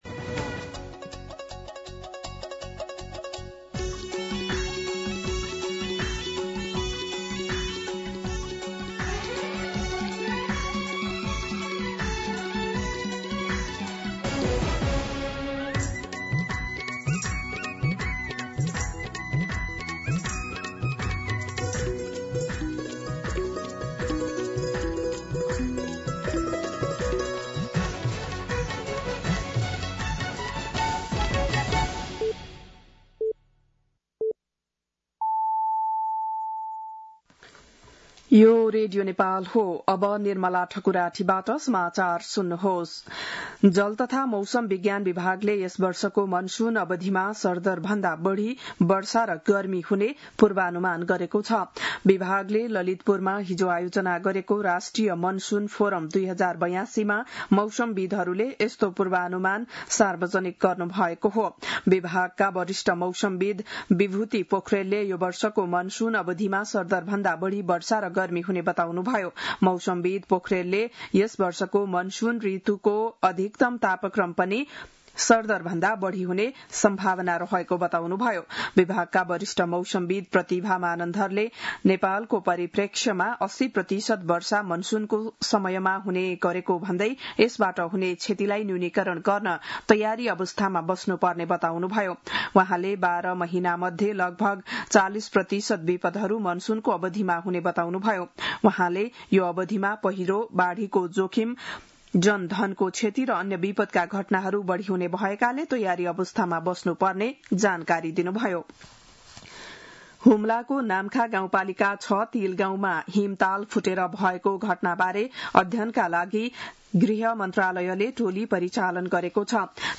बिहान ११ बजेको नेपाली समाचार : ८ जेठ , २०८२